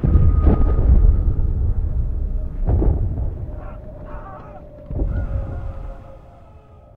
Alarm2_10.ogg